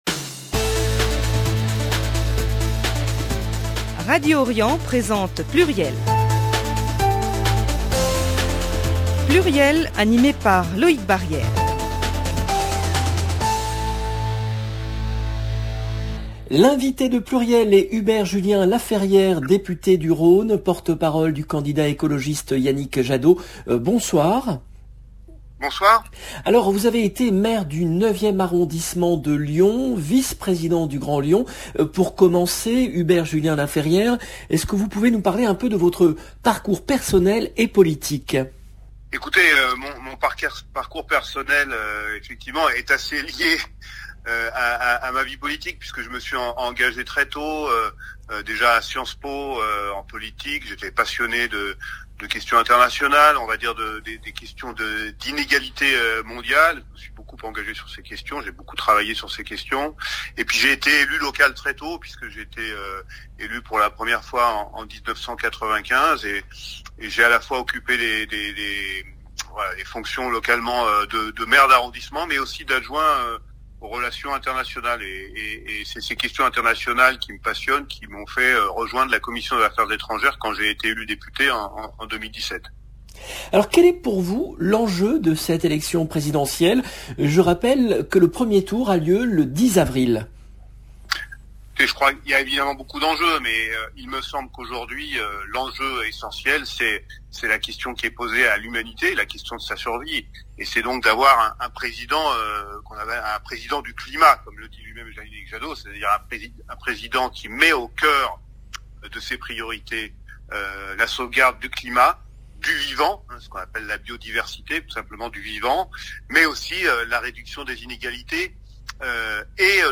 L’invité de PLURIEL est Hubert Julien-Laferrière , député du Rhône, porte-parole du candidat écologiste Yannick Jadot